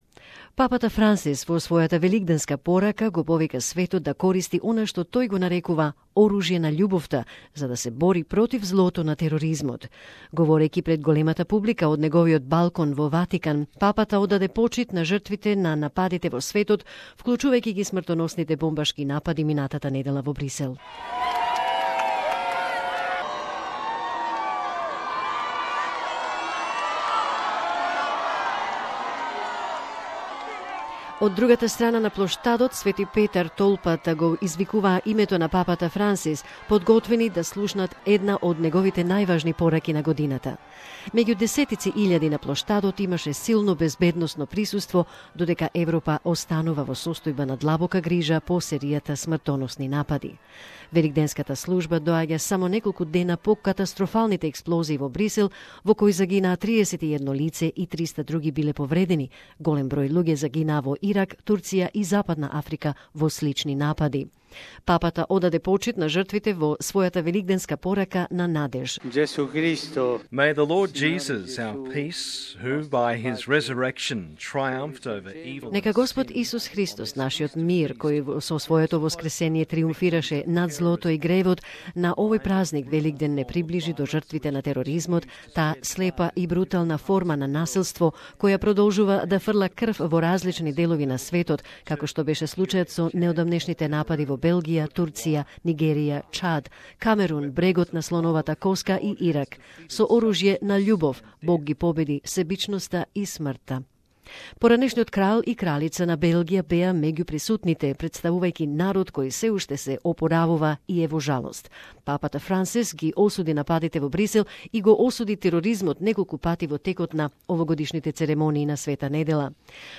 Pope Francis has urged the world to use what he calls "weapons of love" to fight the evil of terrorism in his annual Easter address. Speaking to a large audience from his balcony at the Vatican, the Pontiff has paid tribute to the victims of attacks worldwide, including last week's deadly bombings in Brussels.